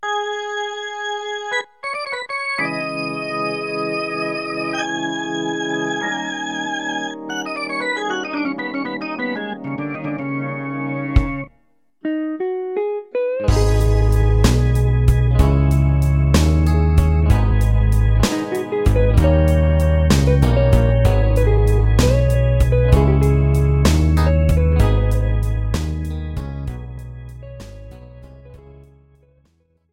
Without Backing Vocals. Professional Karaoke Backing Track.